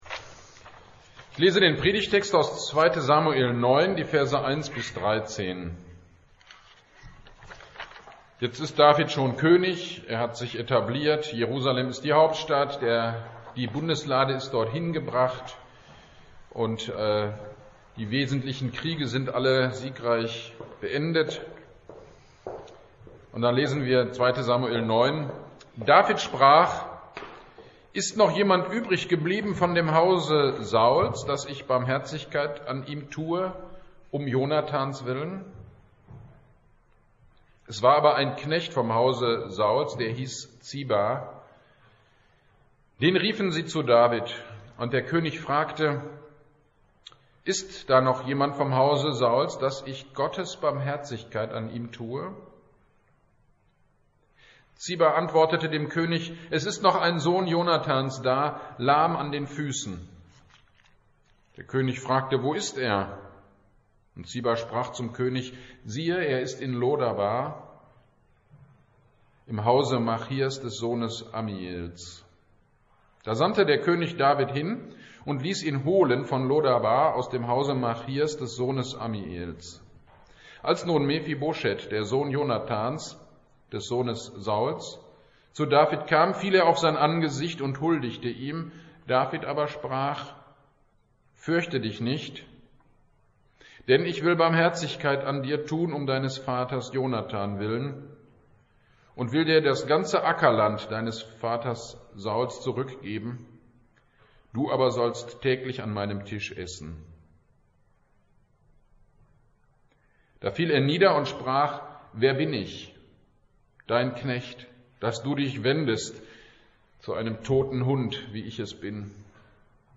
Predigt am 03.04.2022 zu 2. Samuel 9 - Kirchgemeinde Pölzig